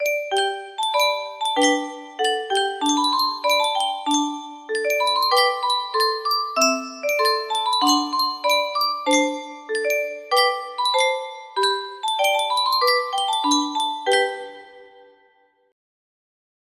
Clone of Yunsheng Spieluhr - Brahms Sandmännchen Y805 music box melody